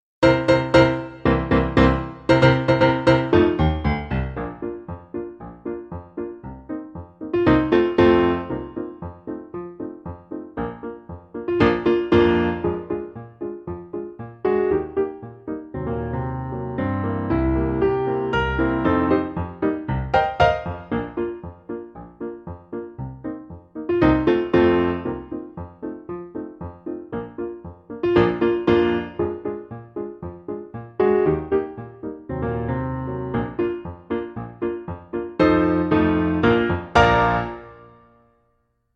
All-I-Do-Is-Dream-Of-You-no-vocals.mp3